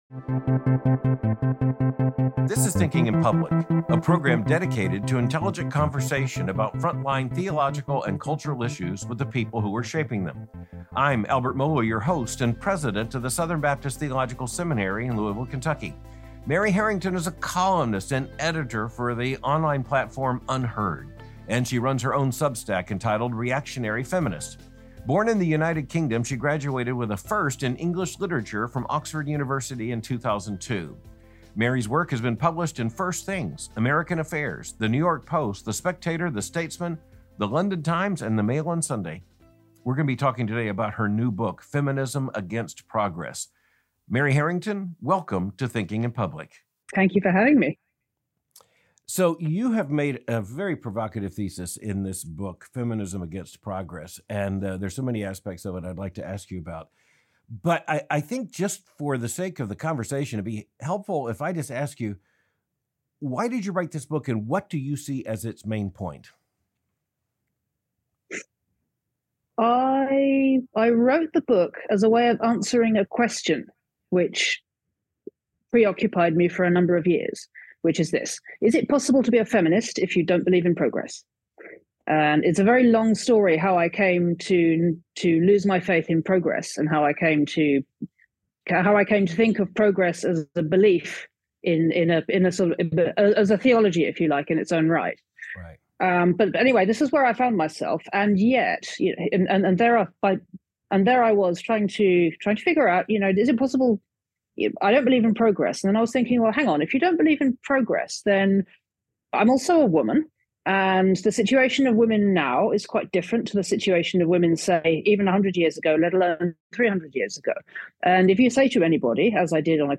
The Sexual Revolution and the Radical Redefinition of Feminism — A Conversation with Mary Harrington